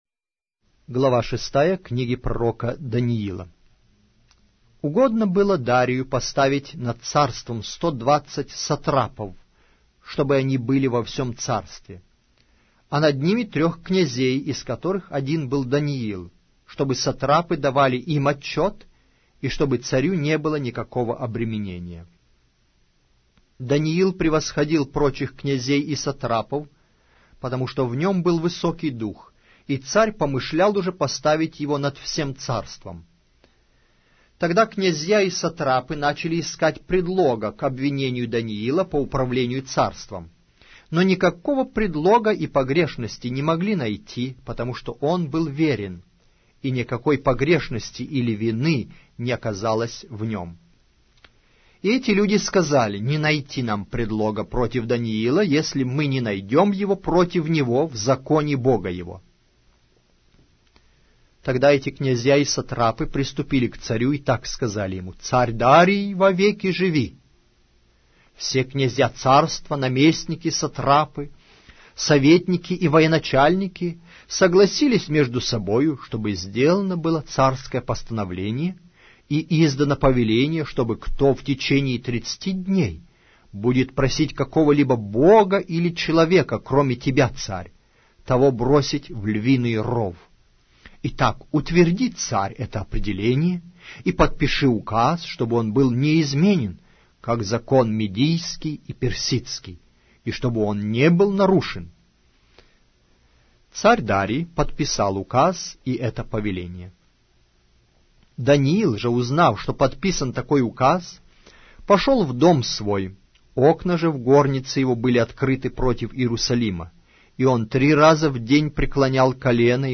Аудио трек Книга Пророка Даниила гл.6-я из книги автор Аудио - Библия